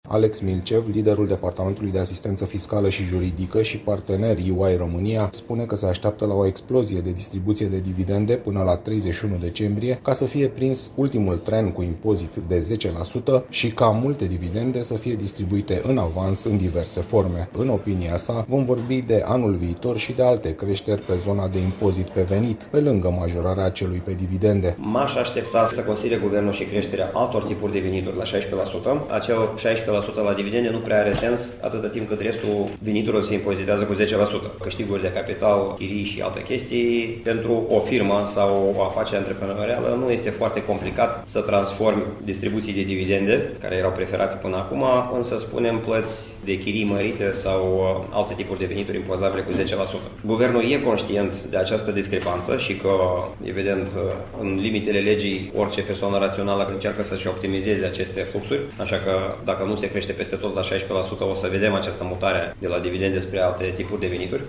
Ei se aşteaptă însă ca şi autorităţile să reacţioneze, prin noi modificări fiscale, după cum relatează redactorul